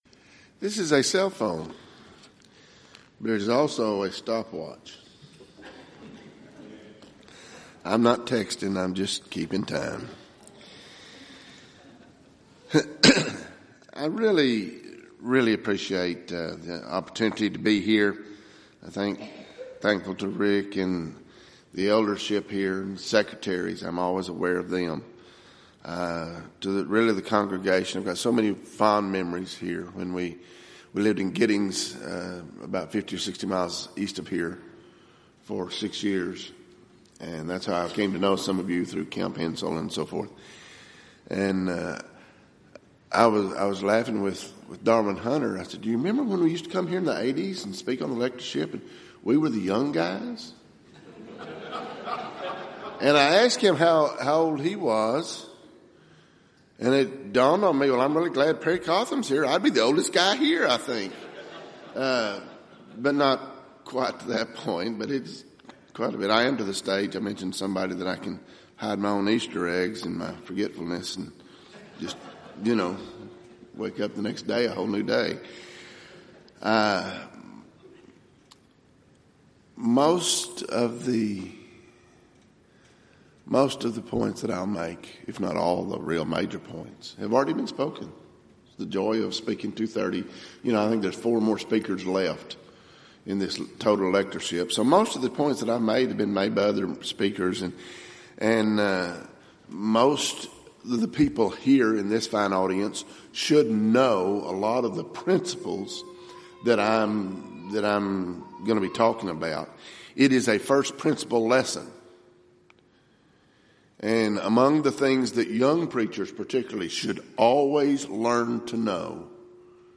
Event: 29th Annual Southwest Lectures
lecture